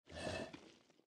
Minecraft Version Minecraft Version snapshot Latest Release | Latest Snapshot snapshot / assets / minecraft / sounds / mob / turtle / idle3.ogg Compare With Compare With Latest Release | Latest Snapshot